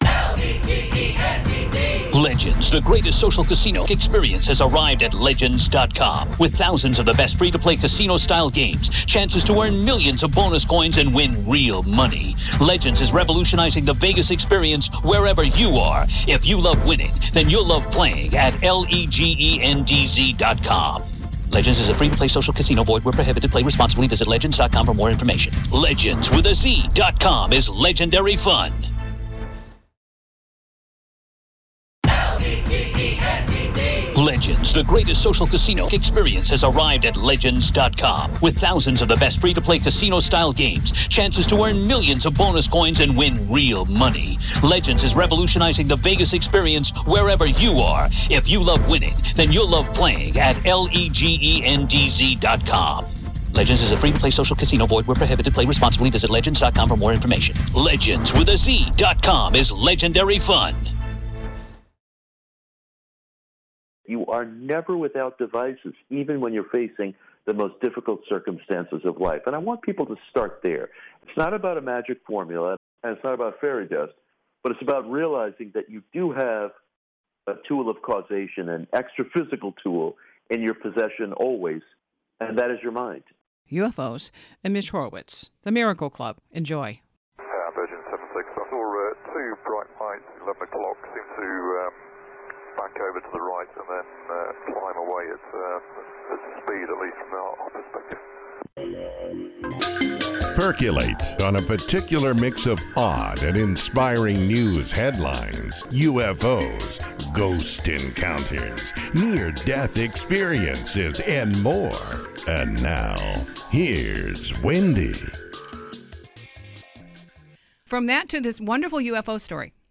- Neville Goddard ~~~ This is the link for more information on the UFO encounter pilot's audio referenced in the beginning of the interview.